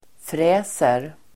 Uttal: [fr'ä:ser]